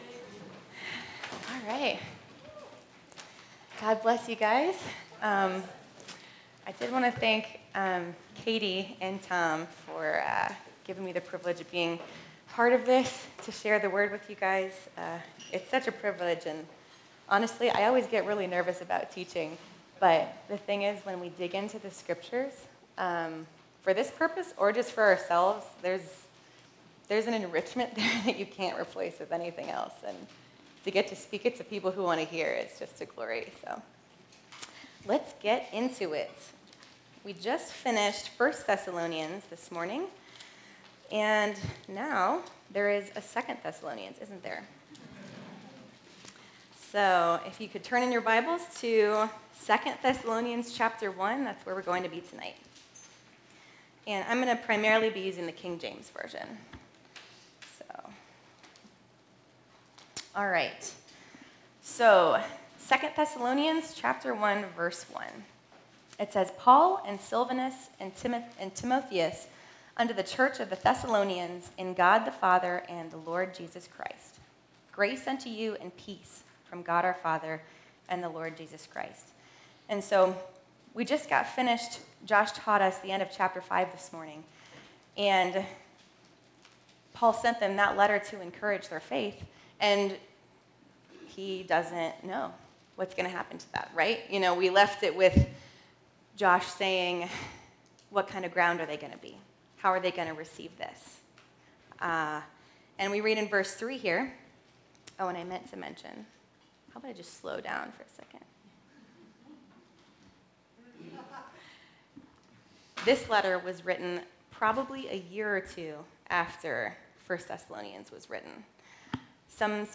2 Thessalonians 1 Our Daily Hope (Family Camp 2024) – Part 10 July 31, 2024 Part 10 in a verse-by-verse teaching series on 1 and 2 Thessalonians with an emphasis on how our hope helps us to live holy lives until Christ returns.